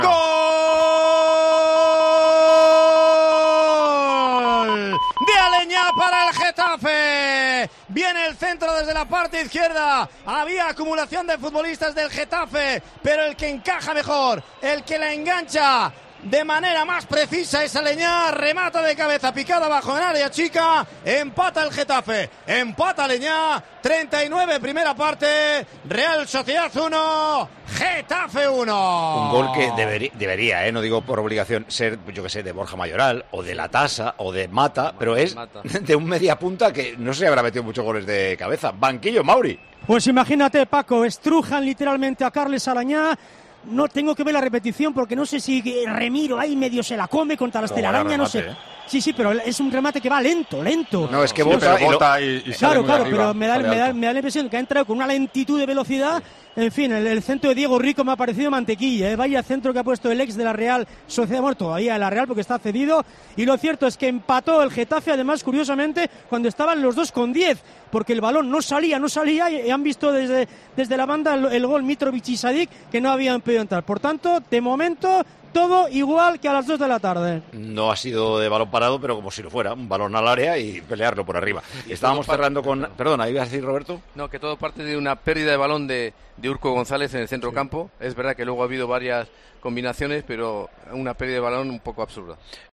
Micrófono de COPE en Anoeta Reale Arena